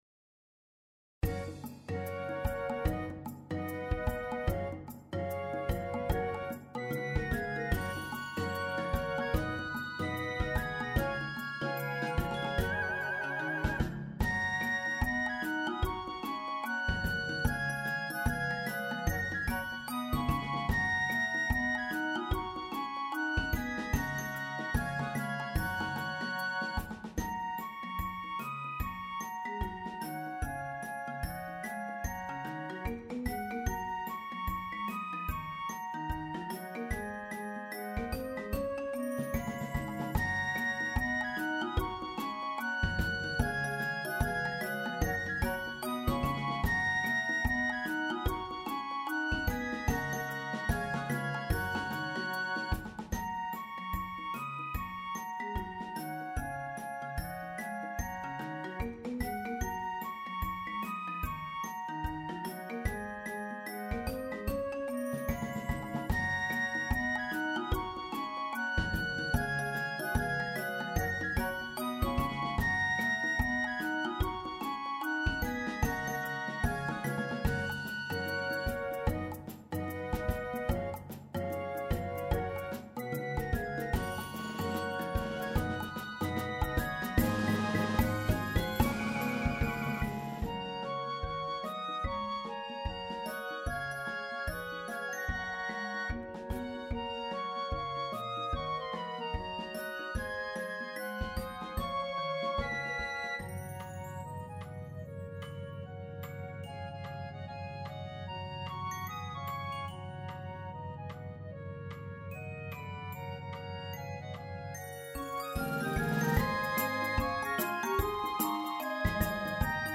Musik: Traditional Spiritual
Klangbeispiel Ces/Fes-Besetzung